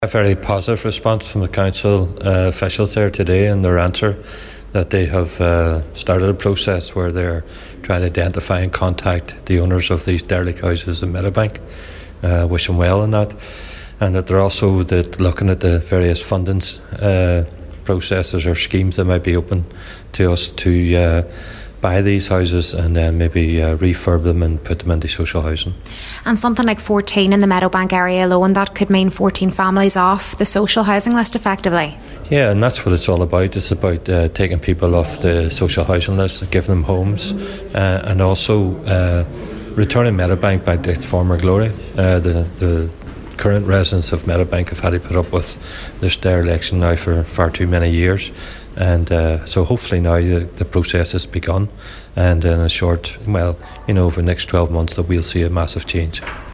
Cllr Gerry McMonagle says if the plans to come to fruition, they will have far reaching benefits for the community there………….